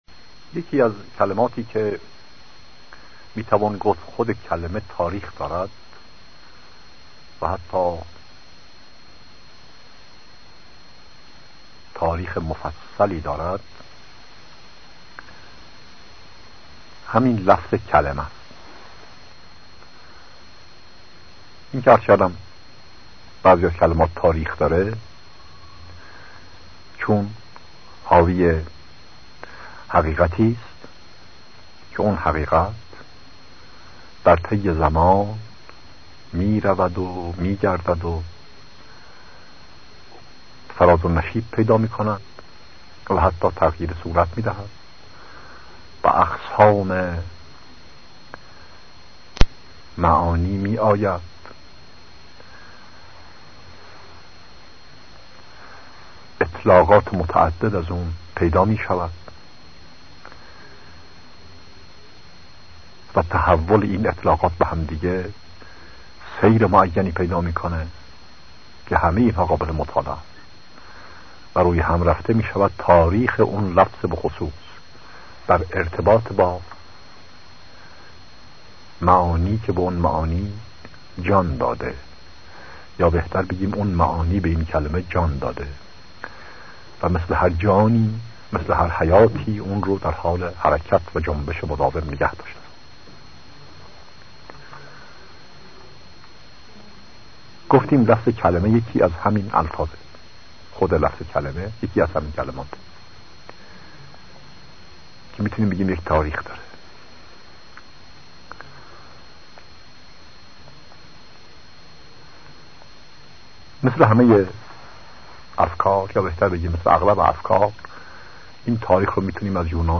دانلود 075 khallaghiyat_e_kalamatollah.mp3 سایر دسته بندیها سخنرانی هایی پیرامون عقاید بهائی 18208 بازدید افزودن دیدگاه جدید نام شما موضوع دیدگاه * اطلاعات بیشتر درباره قالب‌بندی متن چه کدی در تصویر می‌بینید؟